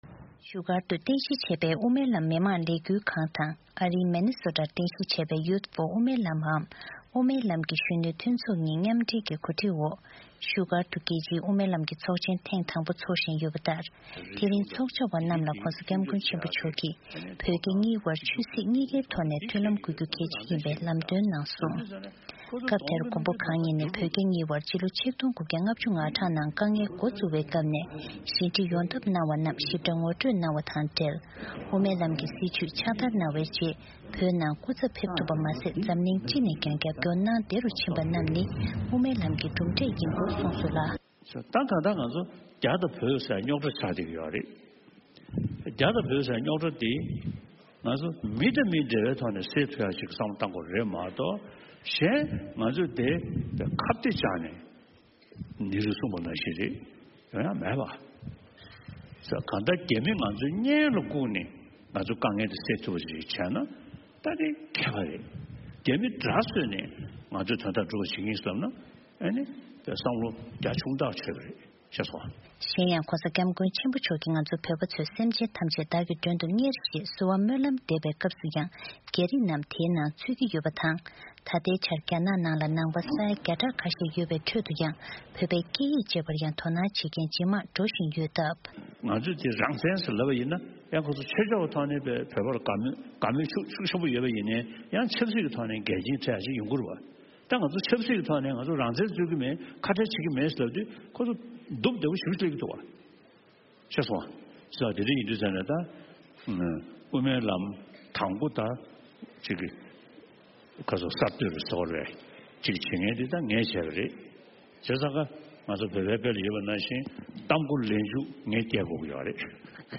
བཞུགས་སྒར་དུ་རྒྱལ་སྤྱིའི་དབུ་མའི་ལམ་གྱི་ཚོགས་ཆེན་ཐེངས་དང་པོ་འཚོགས་བཞིན་ཡོད་པ་ལྟར་ཕྱི་ཚེས་༣༠་ཉིན་སྤྱི་ནོར་༧གོང་ས་སྐྱབས་མགོན་ཆེན་པོ་མཆོག་གིས་དབུ་མའི་ལམ་གྱི་སྲིད་བྱུས་ཀྱི་བྱུང་རིམ་སྐོར་དང་བོད་རྒྱ་གཉིས་ལ་ཆོས་སྲིད་གཉིས་ཐད་དུ་མཐུན་ལམ་དགོས་རྒྱུ་གལ་ཆེན་ཡིན་ཞེས་ཚོགས་བཅར་བ་ཚོར་བཀའ་སློབ་གནང་བའི་སྐོར།